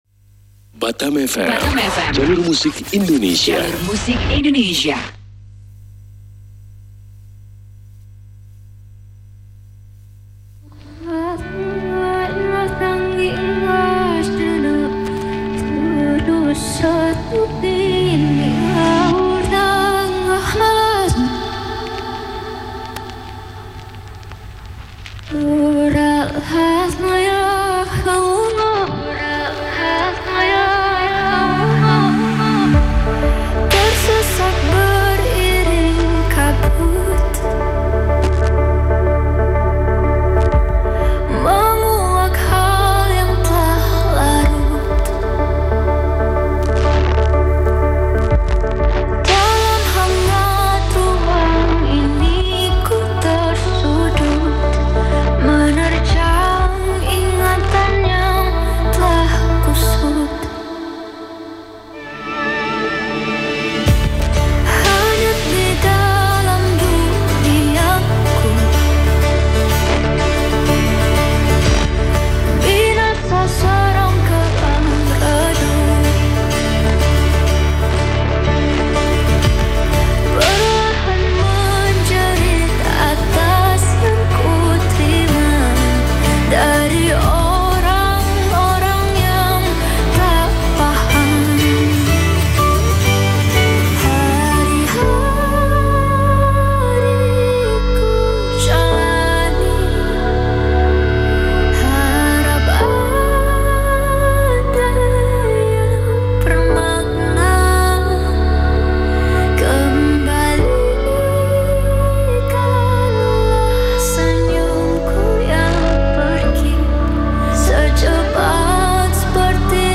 Station Name: Batam FM FREQ: 100.7 MHz MODULATION: FM RECORD PLACE: BCS MALL PARK NOTICE: Station is transmitting in Stereo, But the recorder can only do mono.